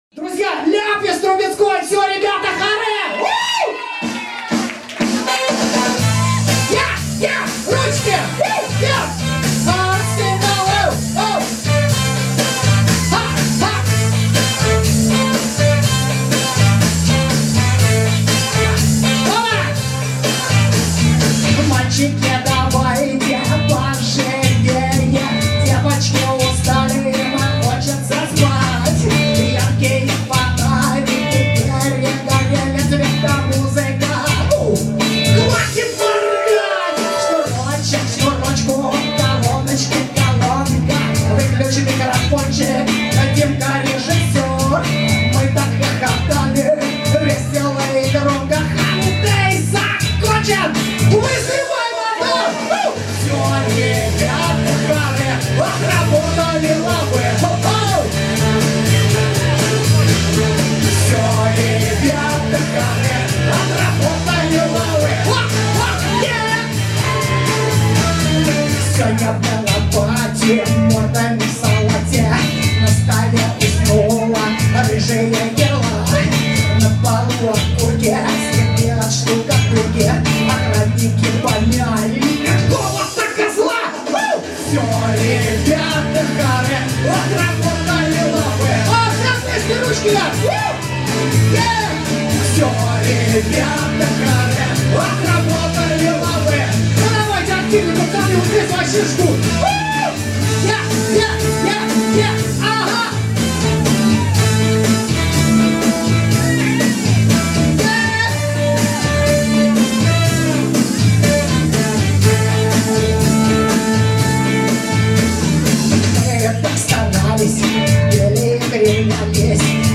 знатно smeh Одно только обидно...как-то все на одной ноте...